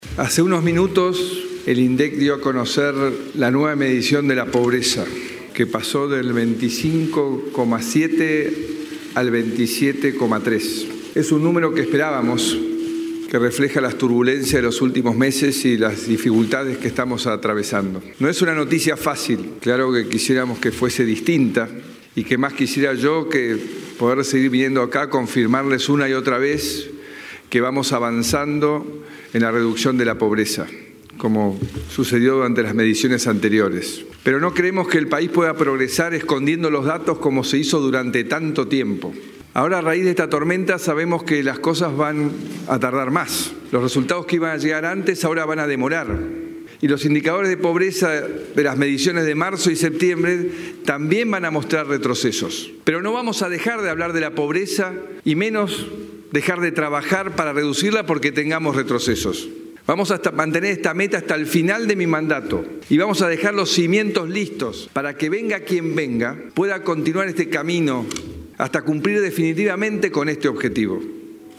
El presidente, Mauricio Macri, ofrece una declaración en Casa Rosada luego de conocerse que la pobreza se ubicó en 27,3% en el primer semestre.